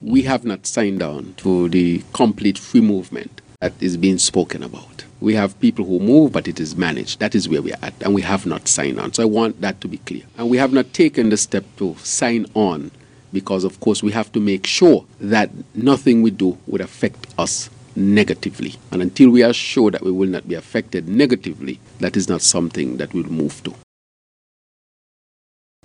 Prime Minister, Dr. Terrance Drew, speaking during a radio program in St. Kitts on Wednesday (Mar. 20th).